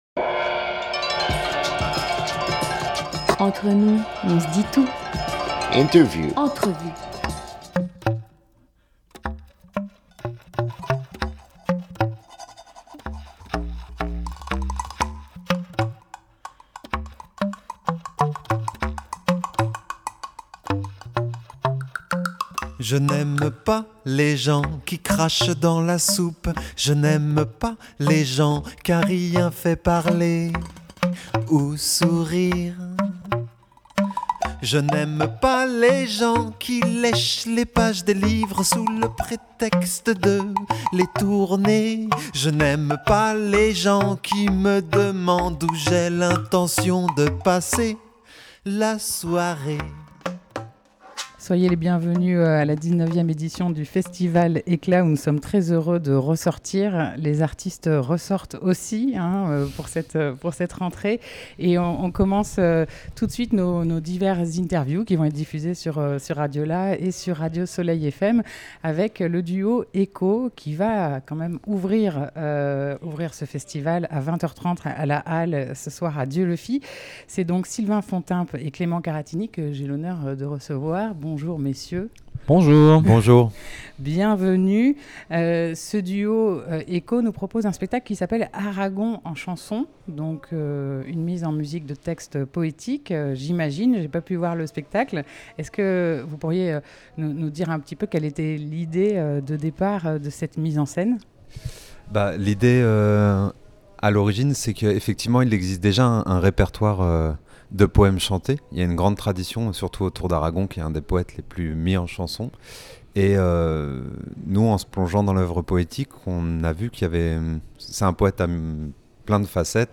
8 septembre 2020 16:44 | Interview